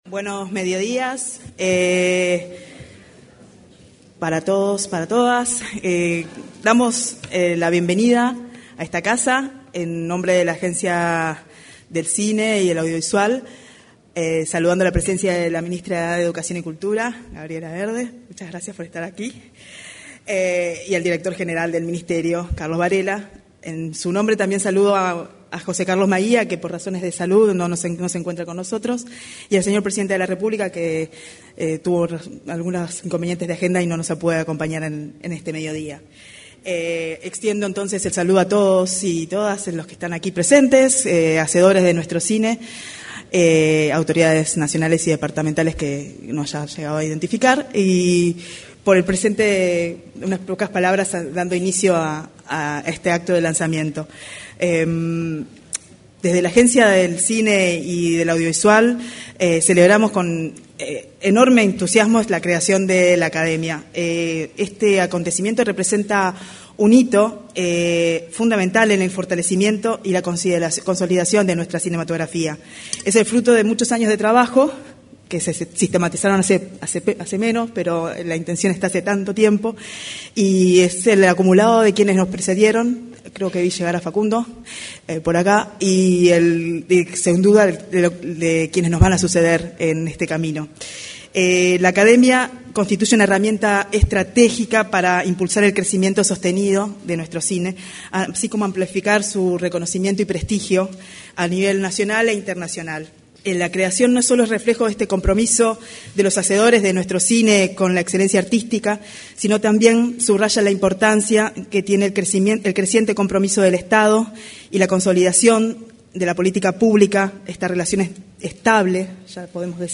Durante la presentación de la Academia de Artes y Ciencias Cinematográficas del Uruguay, se expresaron la presidenta de la Agencia del Cine y el